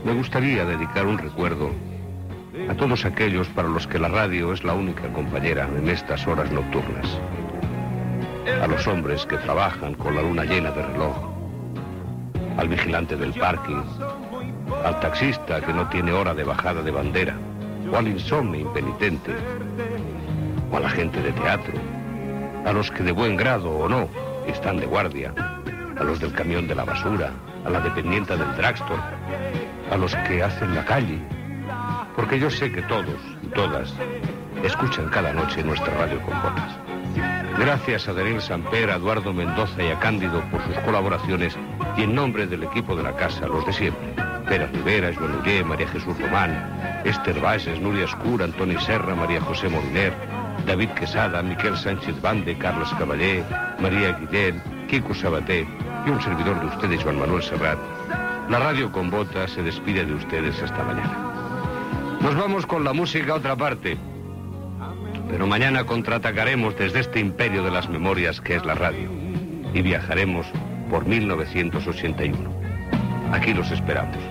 Presentador/a Serrat, Joan Manuel